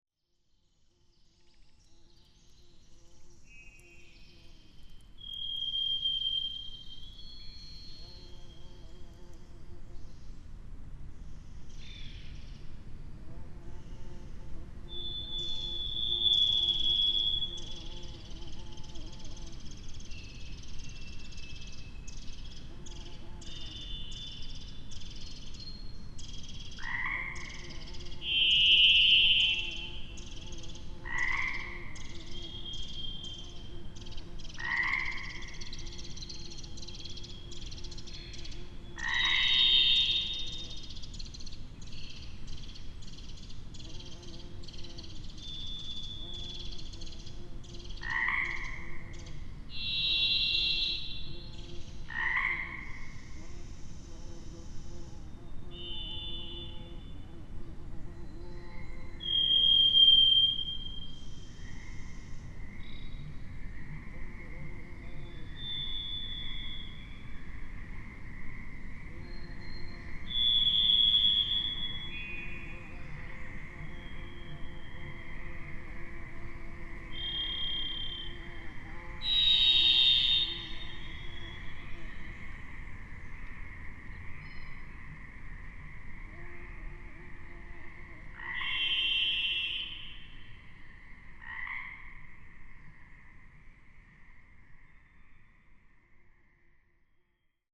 One Square Inch of Silence is very possibly the quietest place in the United States. It is an independent research project located in the Hoh Rain Forest of Olympic National Park, which is one of the most pristine, untouched, and ecologically diverse environments in the United States.